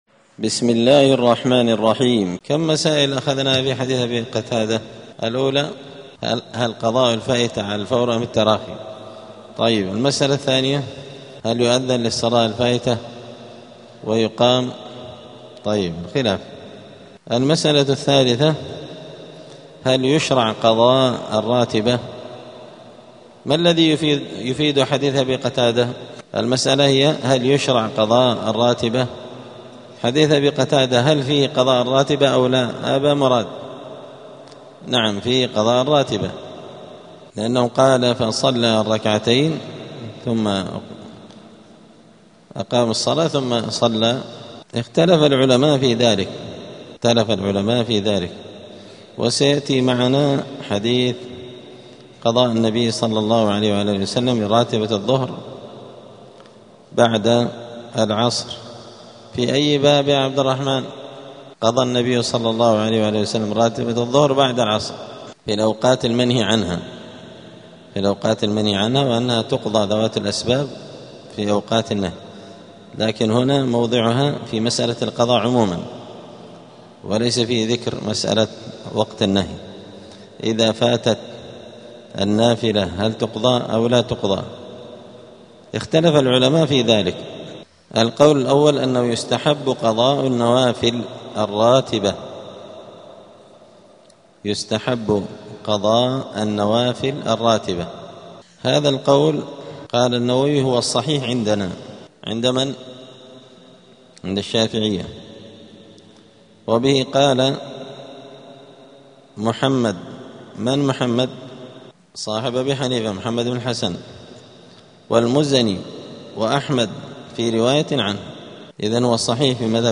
دار الحديث السلفية بمسجد الفرقان قشن المهرة اليمن
*الدرس الخامس والأربعون بعد المائة [145] {هل يشرع قضاء الراتبة}*